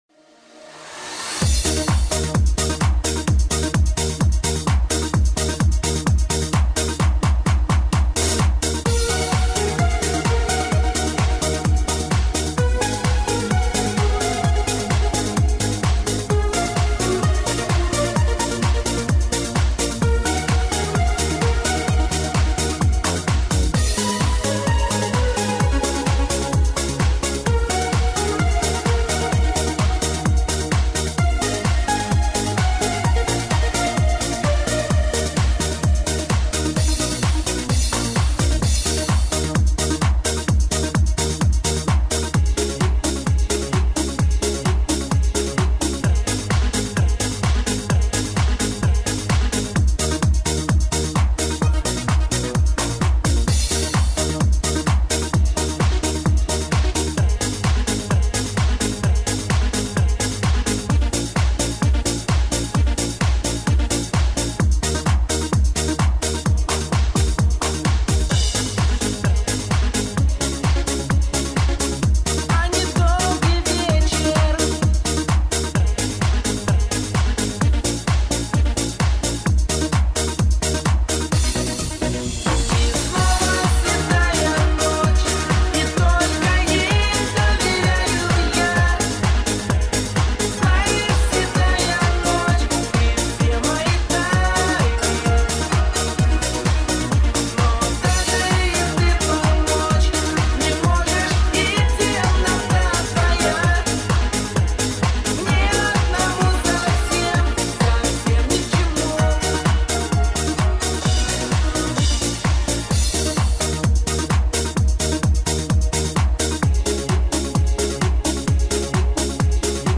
минусовка